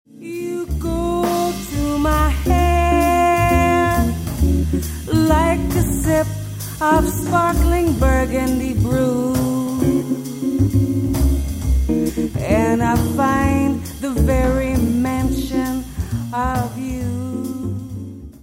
voice
guitar
bass
drums
tenor saxophone